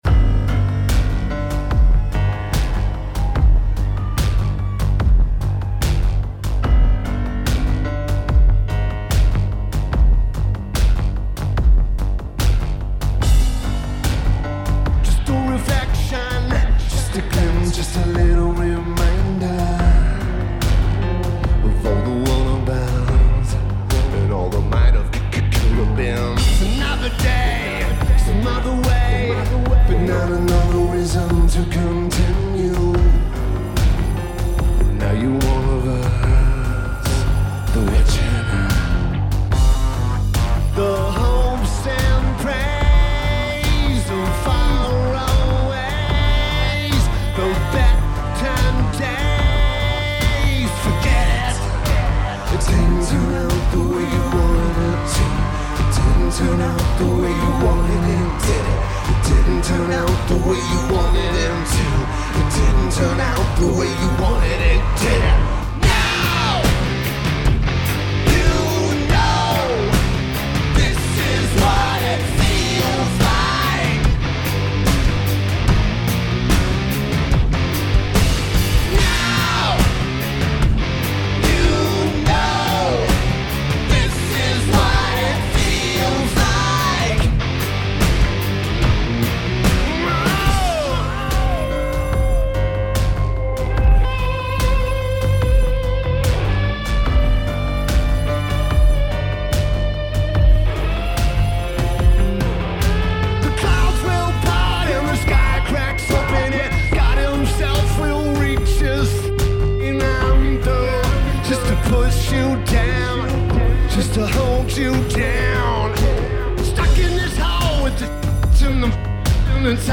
Lineage: Audio - PRO, Soundboard